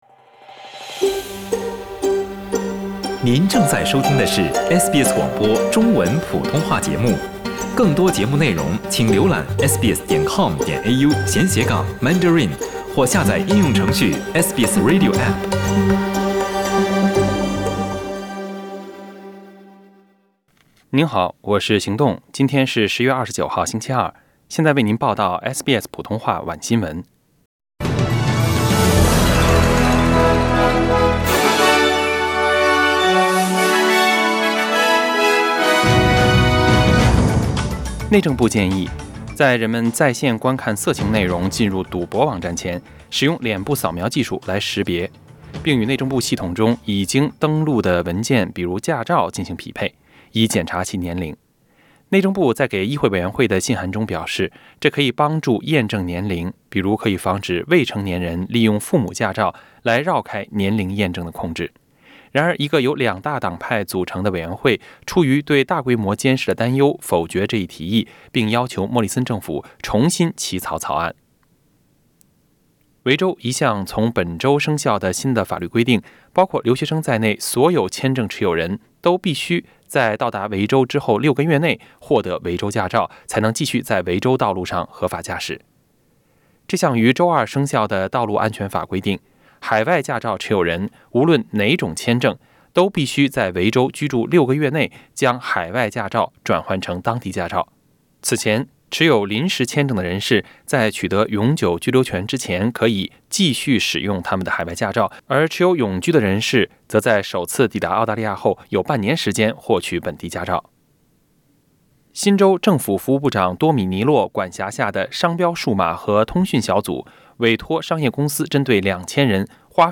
SBS晚新闻 （10月29日）